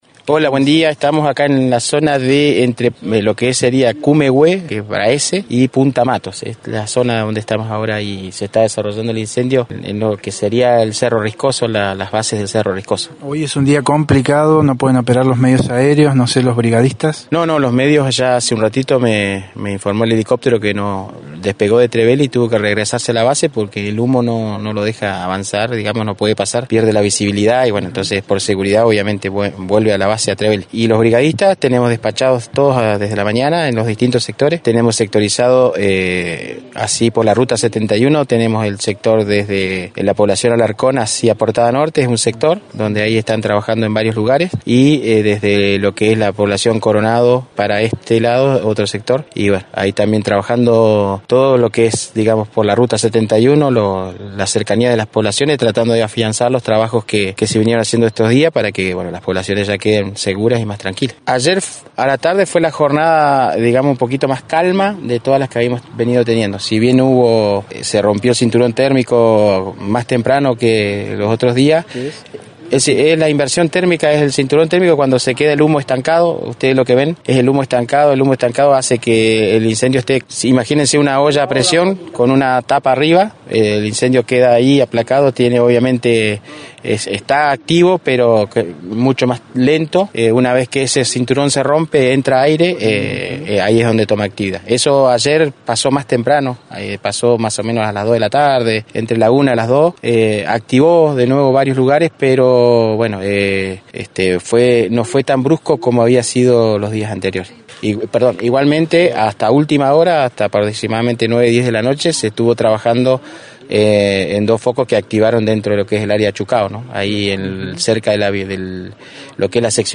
Minutos más tarde los medios de comunicación fueron llevados hasta la zona de Arrayanes, donde a una distancia segura del incendio pudieron ver algo del combate al fuego.